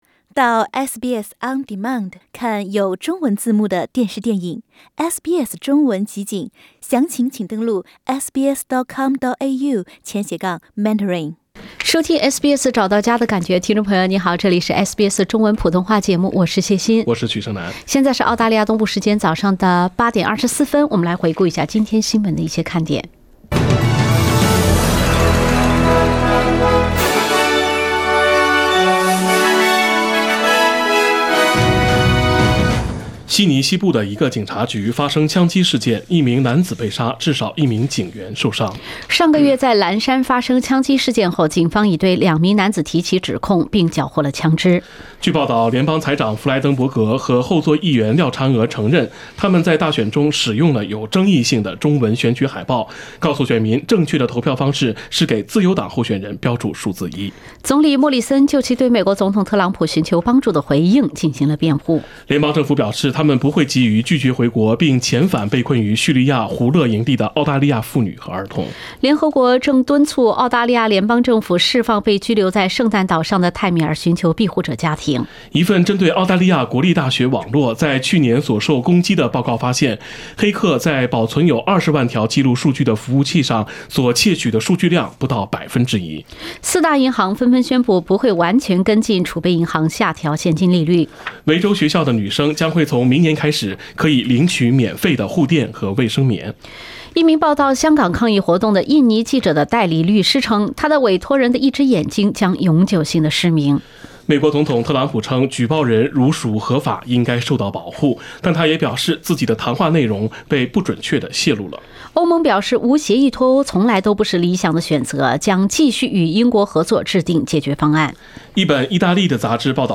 SBS早新闻（10月3日）